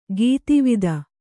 ♪ gītivida